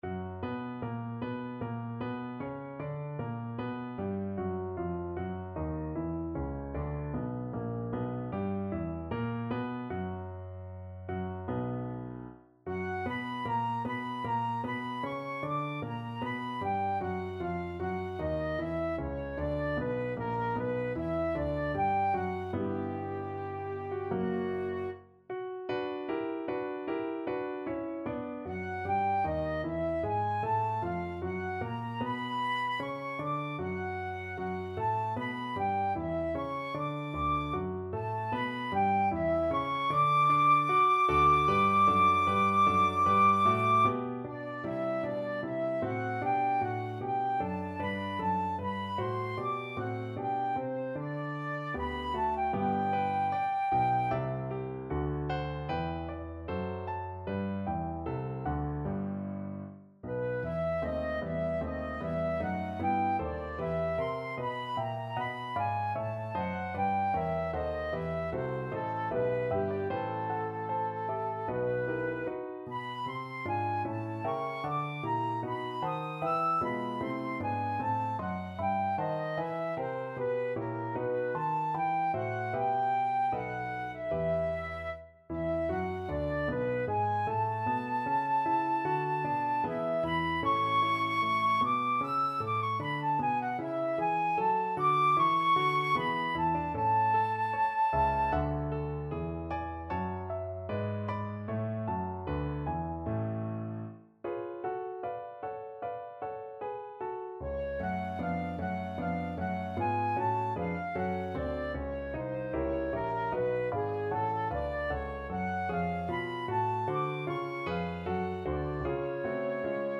Flute
B minor (Sounding Pitch) (View more B minor Music for Flute )
Larghetto (=76)
Classical (View more Classical Flute Music)